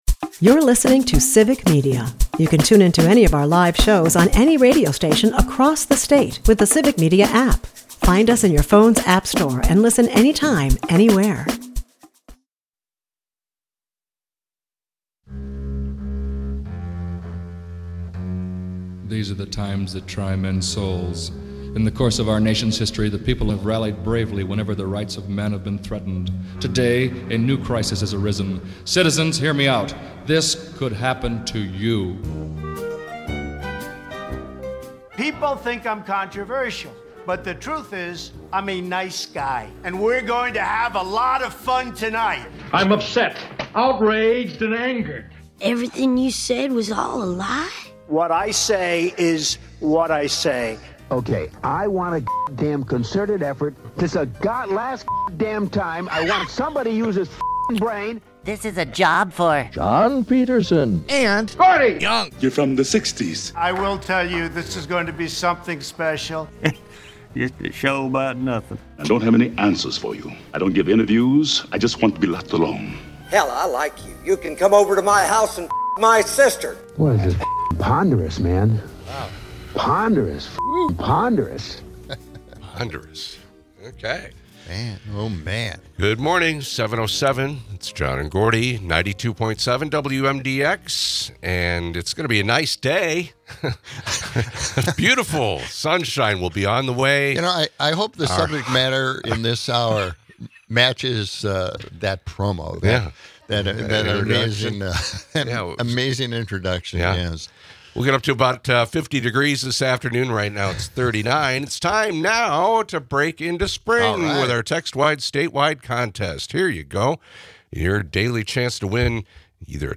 Later in the show, back on the topic of "Liberation Day," from Stephen Colbert, we hear some holiday carols to commemorate the occasion.